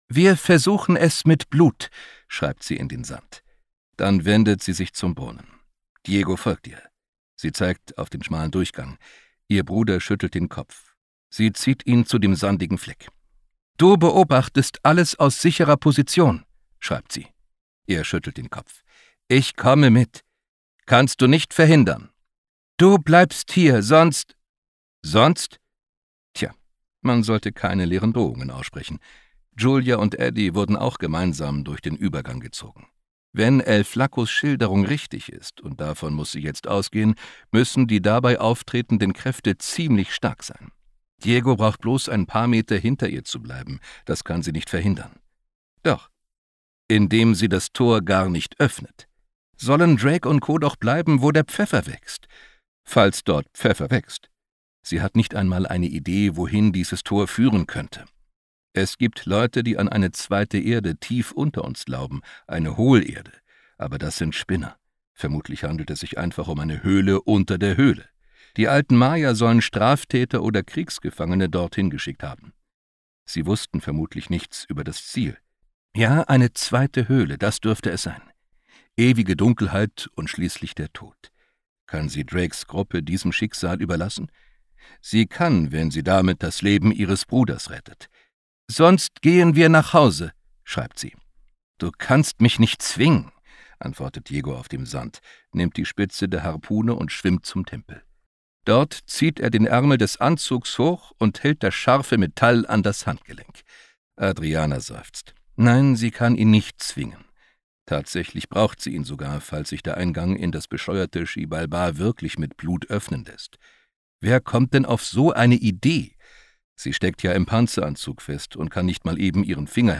Fantasy und Science Fiction Hörbücher
Ungekürzte Lesung